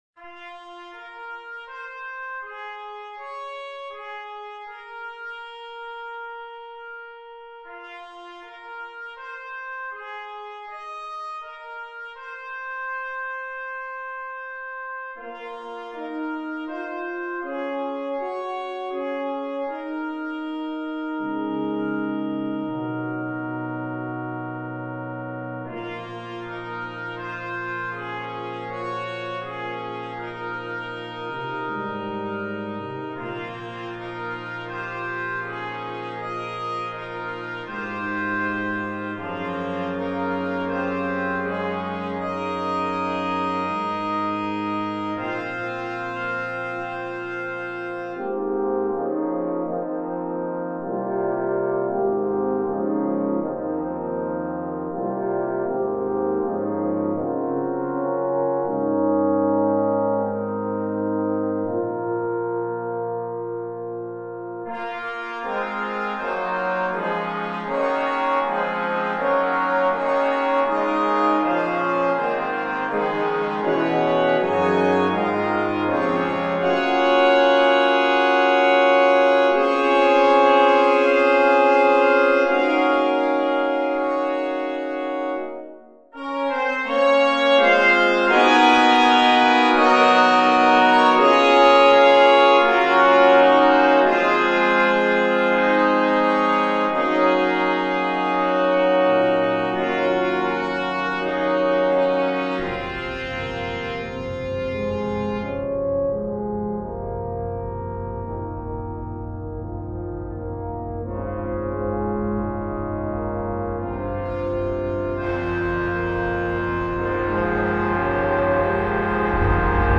Voicing: 12 Brass and Percussion